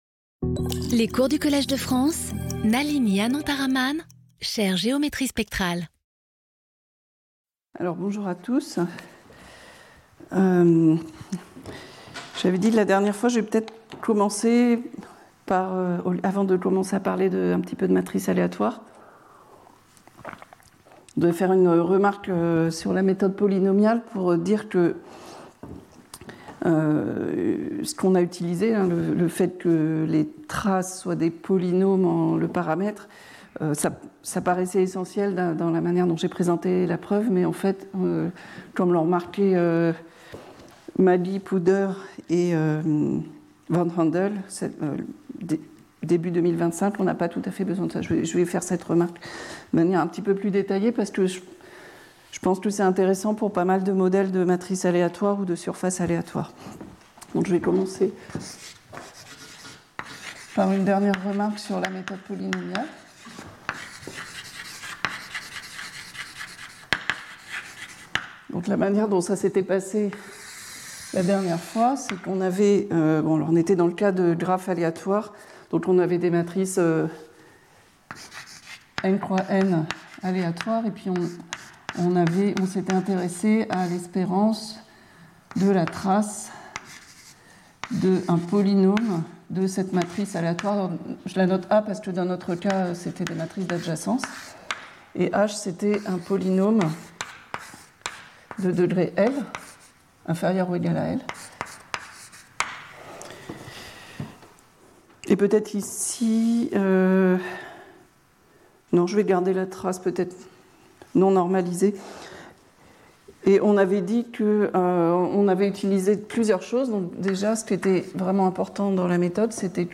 Speaker(s) Nalini Anantharaman Professor at the Collège de France